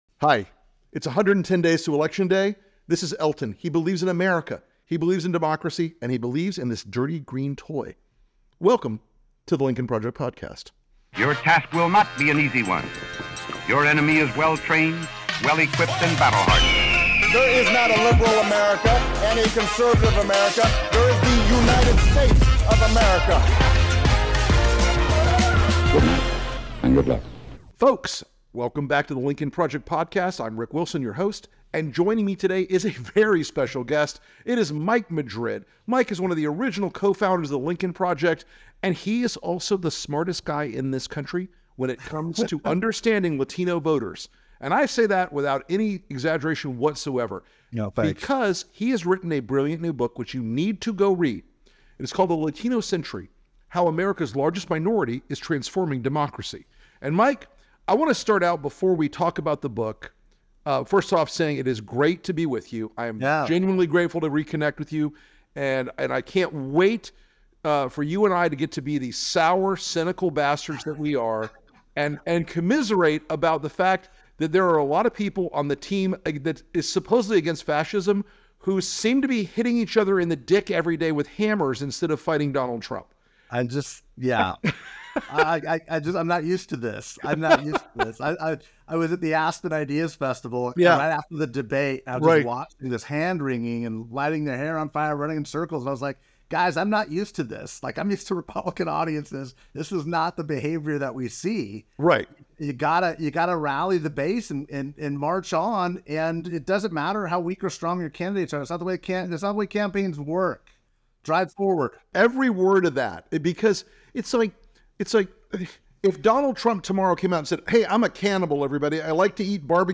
Rick Wilson speaks with fellow Lincoln Project co-founder Mike Madrid, as they delve into his new book ‘The Latino Century: How America's Largest Minority is Transforming Democracy’ and the crucial role of Latino voters in American democracy. They discuss the rise of younger Latino voters, the importance of economic issues and border security, and the need for creative targeted messaging on platforms like WhatsApp and TikTok.